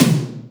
• High Quality Acoustic Tom Drum Single Hit D Key 32.wav
Royality free tom single hit tuned to the D note. Loudest frequency: 1512Hz
high-quality-acoustic-tom-drum-single-hit-d-key-32-LAV.wav